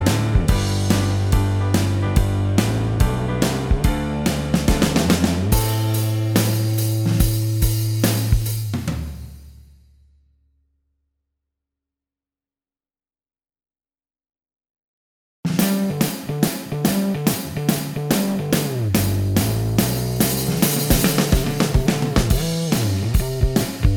Minus All Guitars Indie / Alternative 3:05 Buy £1.50